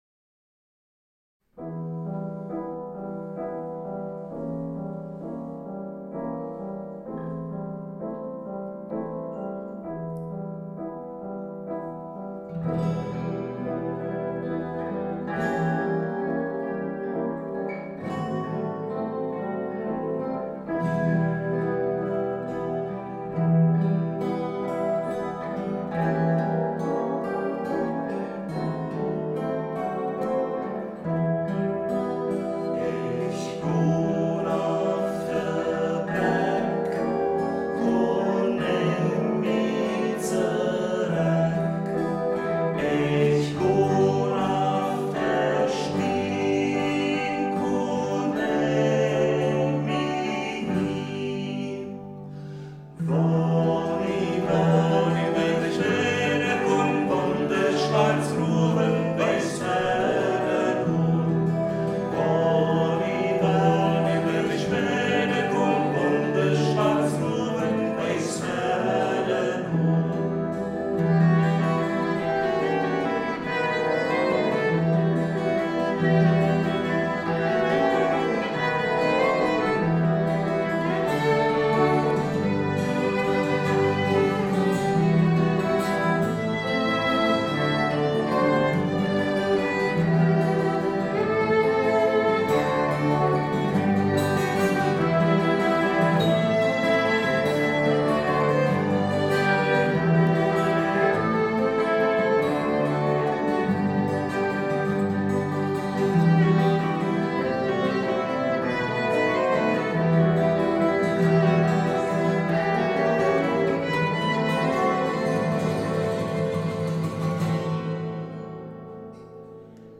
Volkslied
Umgangss�chsisch
Wonn de schwarz Ruowe wei� Feddern hun Ortsmundart : M�hlbach